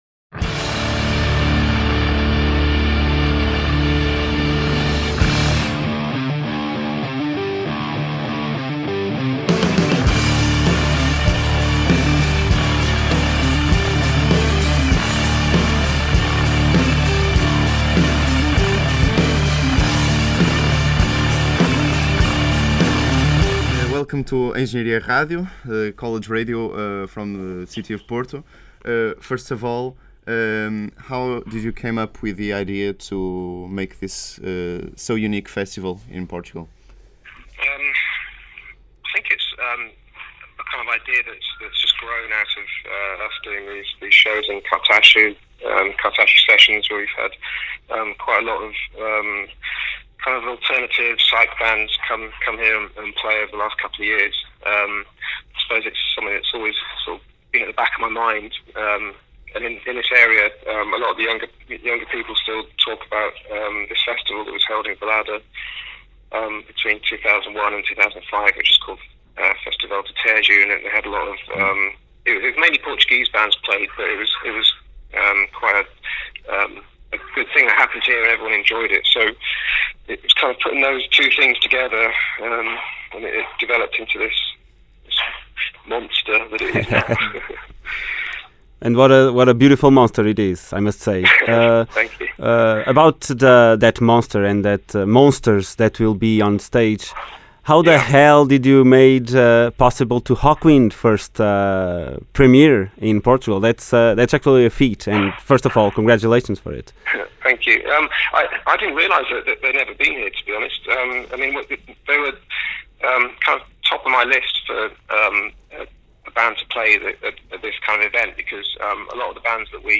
Entrevista
entrevista_reverence.mp3